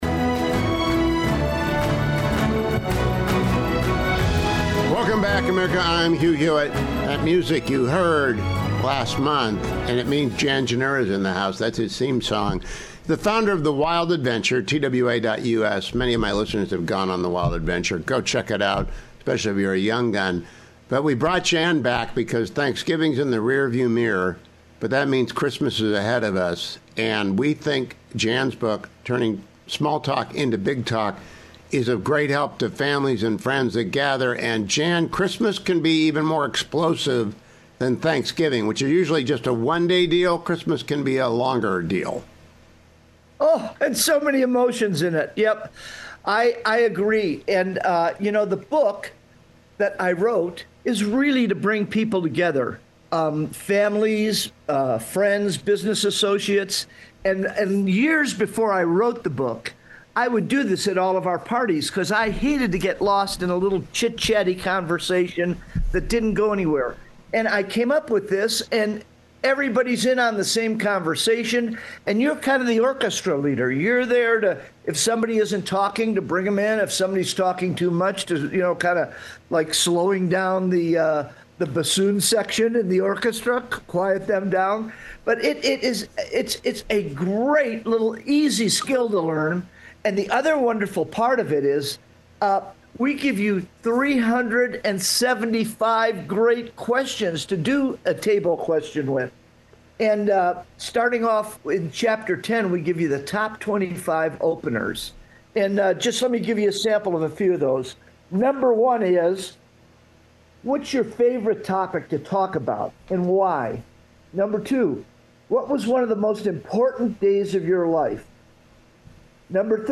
HUGH HEWITT INTERVIEW